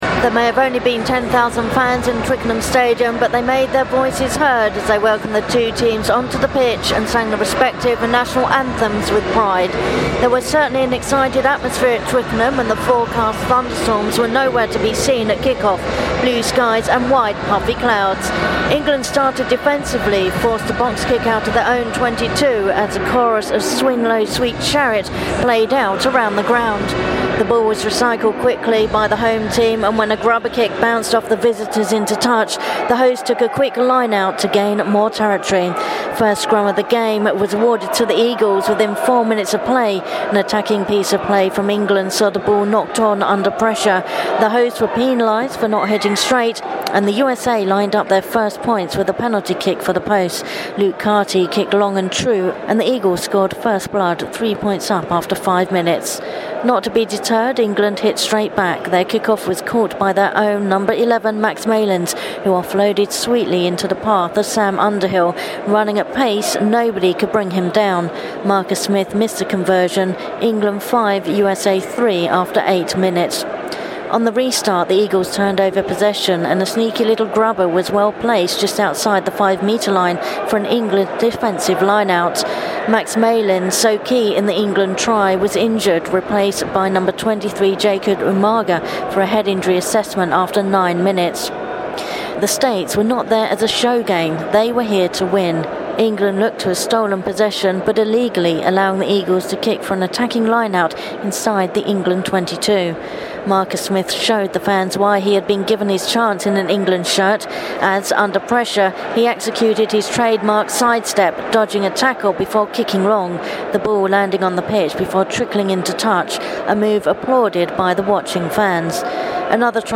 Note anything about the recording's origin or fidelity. England vs USA: First-Half Report from Twickenham Stadium